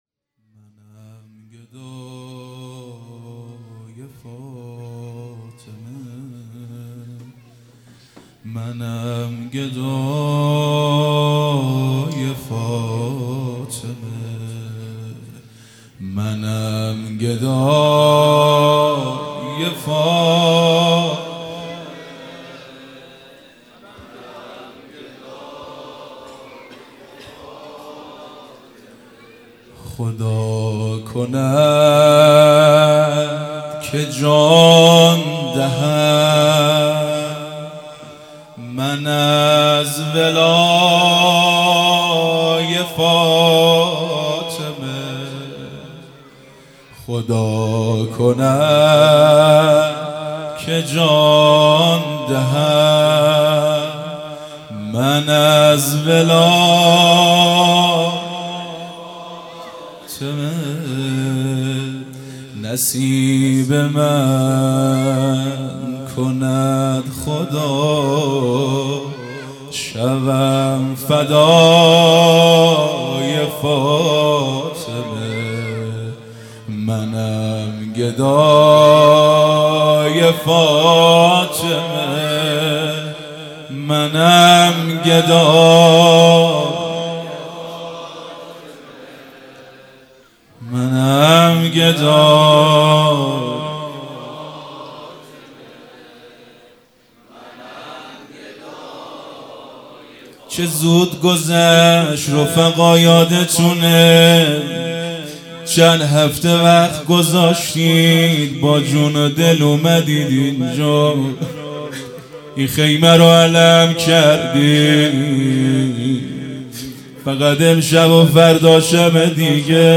0 0 روضه | منم گدای فاطمه مداح
فاطمیه دوم_شب هفتم